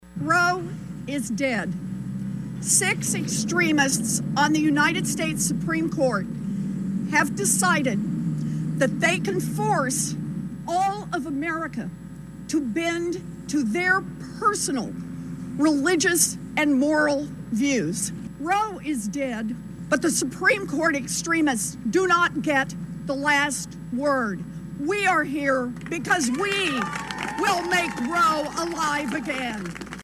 The state’s two Senators have also responded to the ruling with our Senior Senator Elizabeth Warren speaking at the State House on Friday.